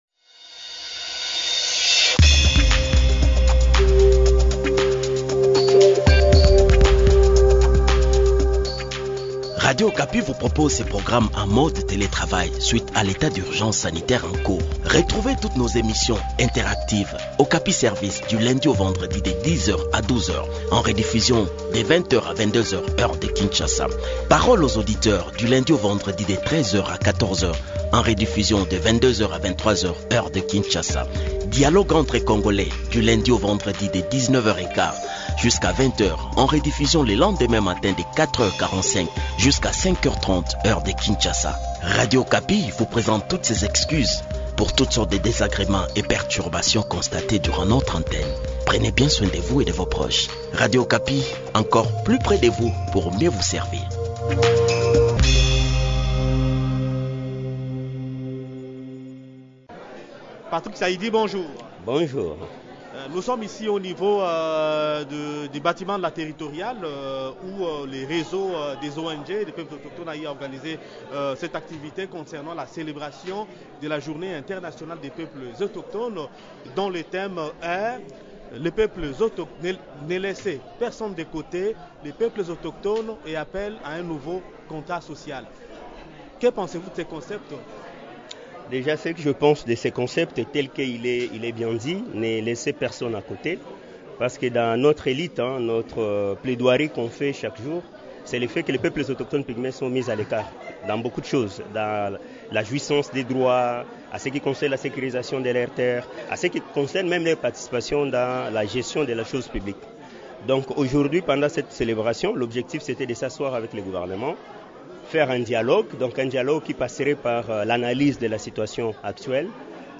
Le point du sujet dans cet entretien